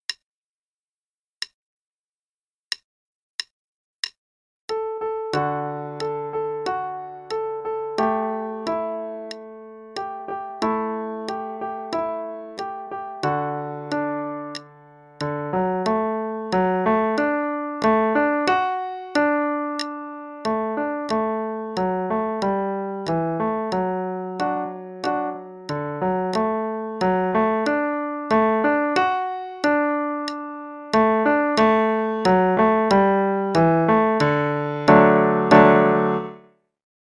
Adult Book 1 (All-in-One): page 121 Aria (qn=92)